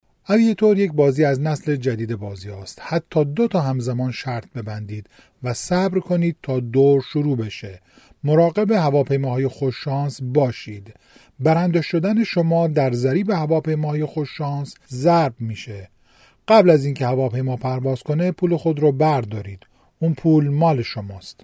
Male
Adult
Game